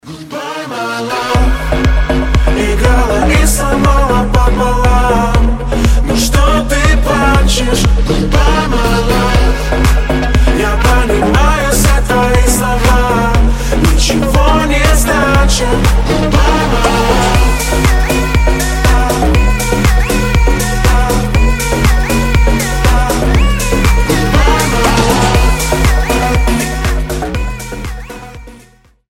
• Качество: 320, Stereo
поп
мужской вокал
dance